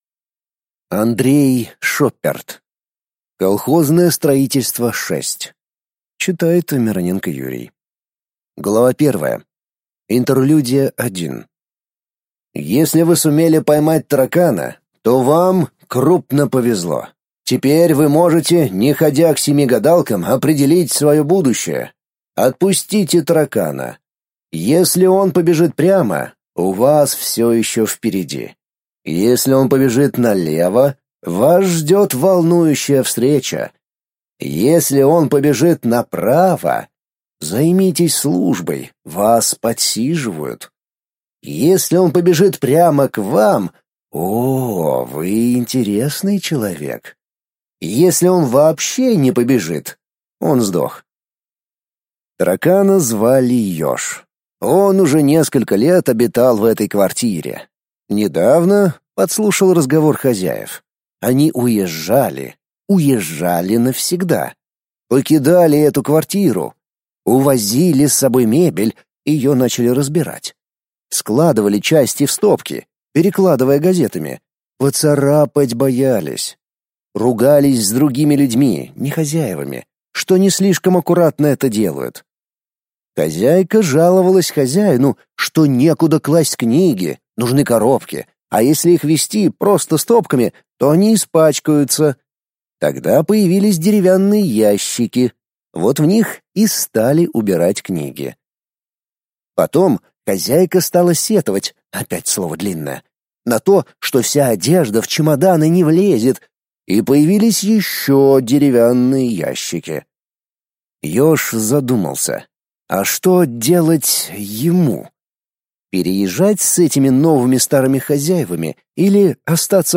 Аудиокнига Аудиокнига на Литрес с 06 .06.23 попаданцы, альтернативная история назад в СССР, перемещение во времени Вот только кресло новое поставишь, утвердишься в нём, а тут «бац» и опять в дорогу.